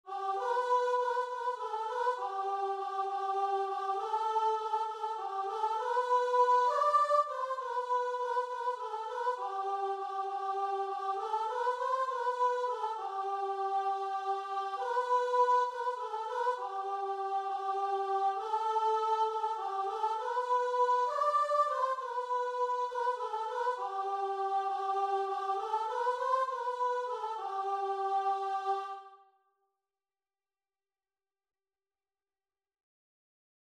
Christian
6/8 (View more 6/8 Music)
Guitar and Vocal  (View more Easy Guitar and Vocal Music)